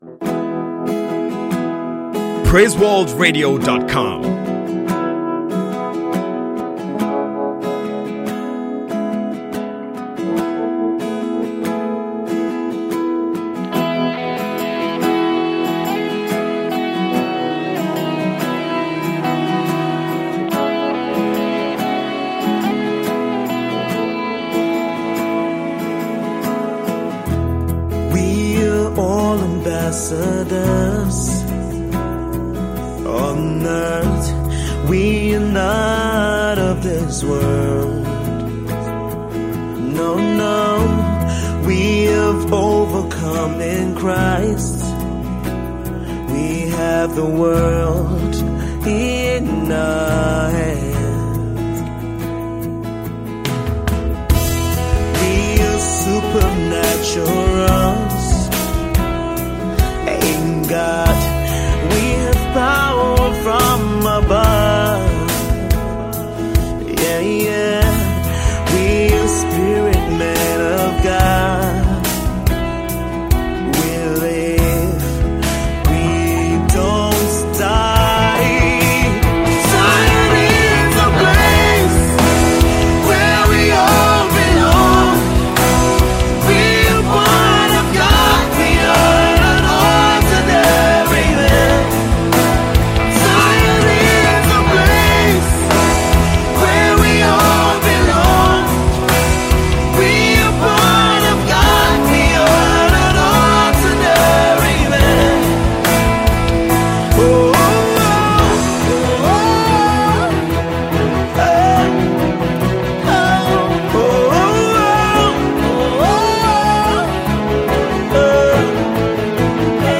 gospel
The worship tune